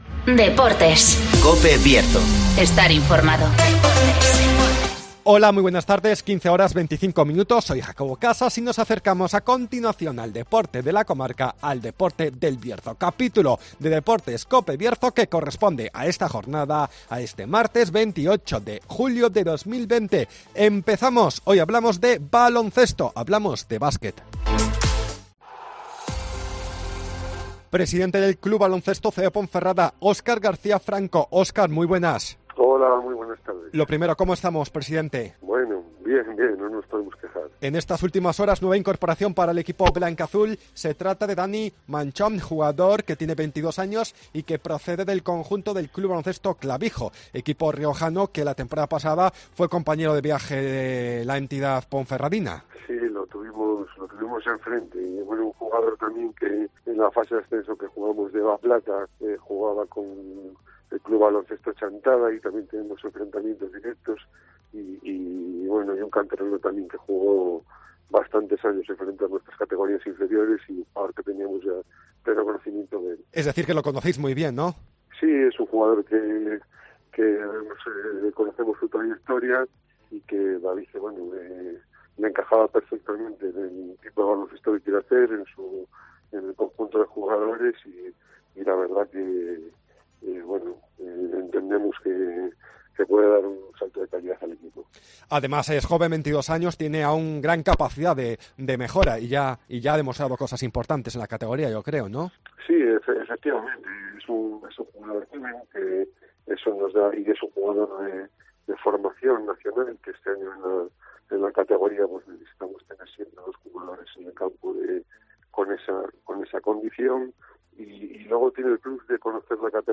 -Actualidad del CB Ciudad de Ponferrada con entrevista